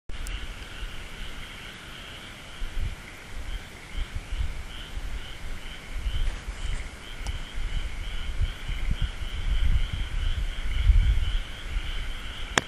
近くの田んぼではカエルが鳴いていました。
カエルの大合唱